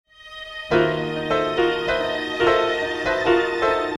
פתיח חמת החלילים